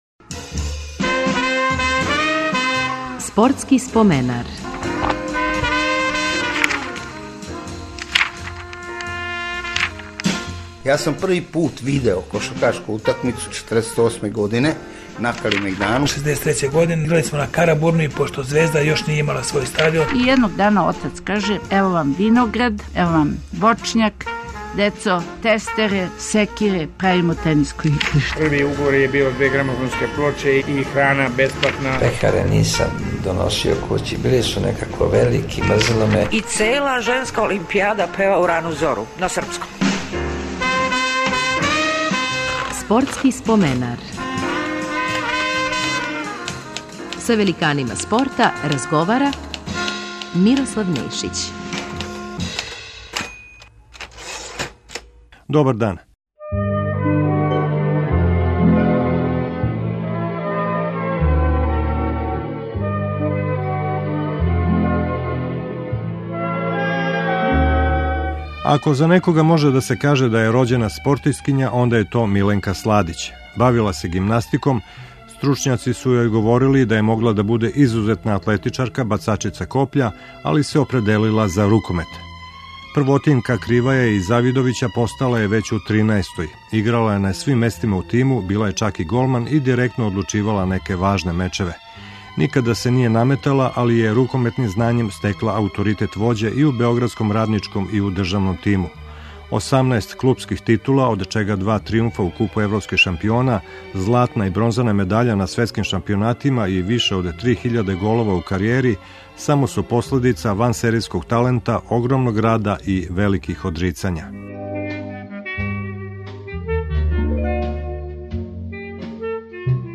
Гост емисије биће рукометашица Миленка Сладић.